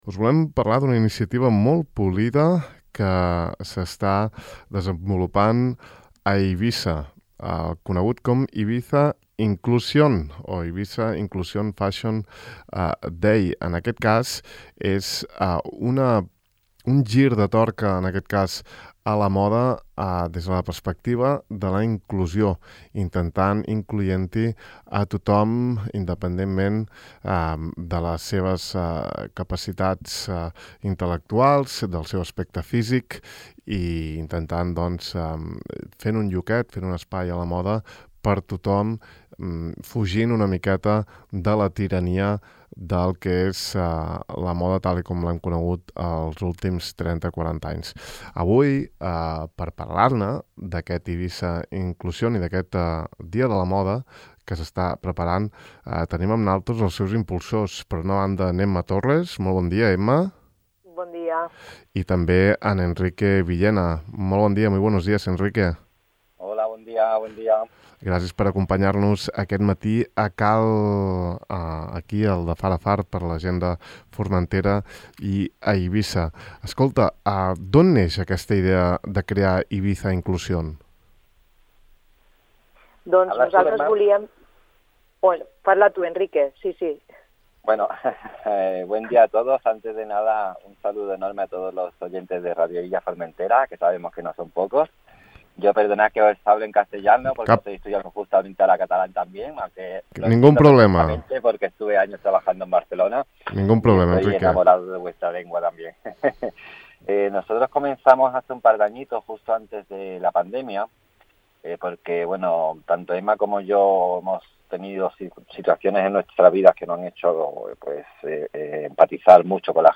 Al De far a far d’avui hem volgut entrevistar